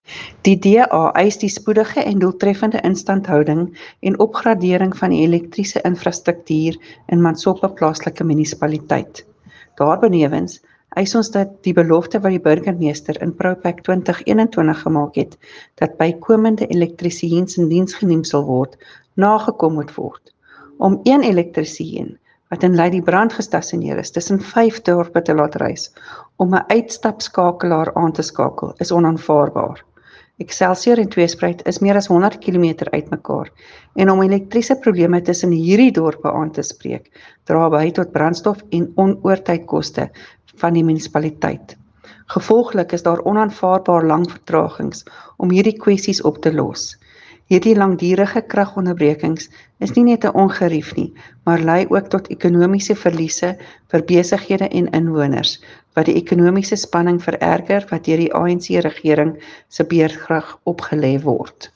Afrikaans soundbites by Cllr Tania Halse and